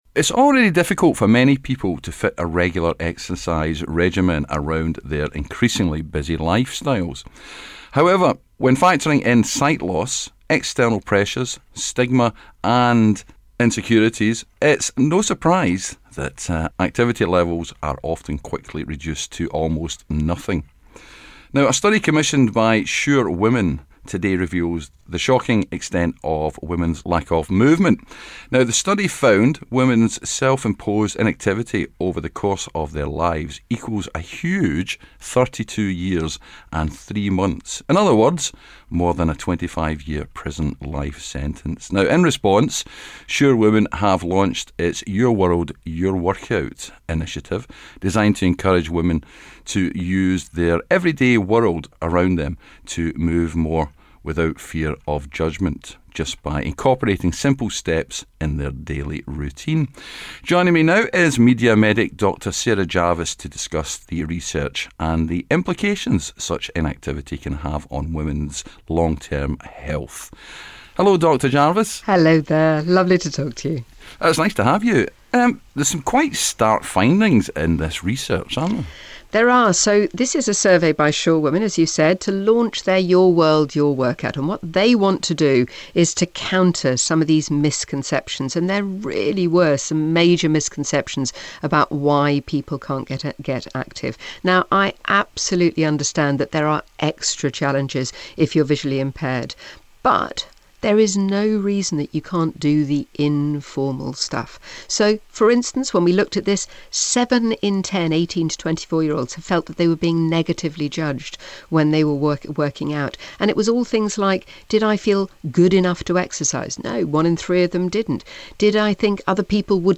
Headliner Embed Embed code See more options Share Facebook X Subscribe New research by Sure Women found that over the course of their lives, women are inactive on average over 32 years. This lack of activity can later negatively impact our lives, leading to multiple health complications, some of which might include sight loss. Media medic Dr Sarah Jarvis spoke with us about the findings and why small changes can make a huge difference.